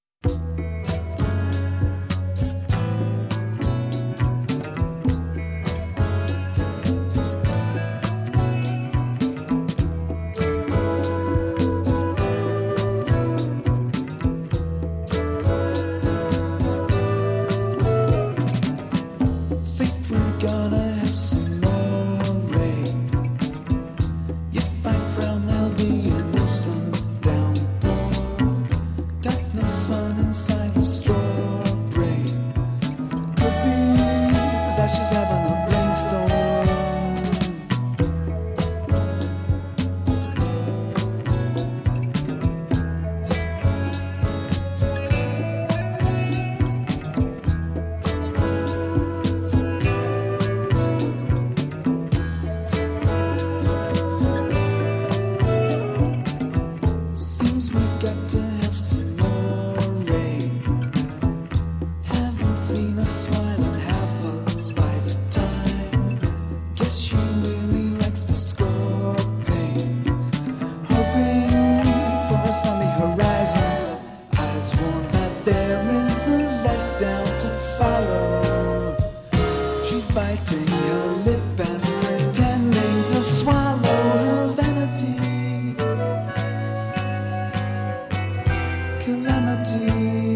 Progressive era